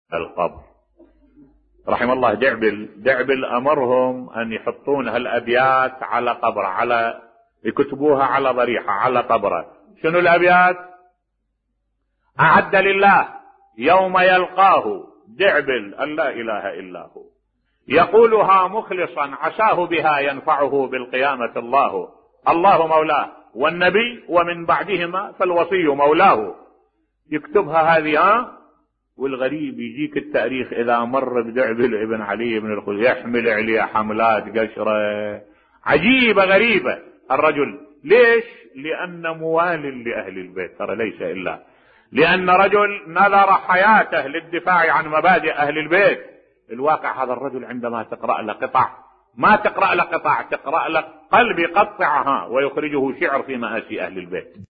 ملف صوتی دعبل الخزاعي ظلم لأنه موالي لأهل البيت (ع) بصوت الشيخ الدكتور أحمد الوائلي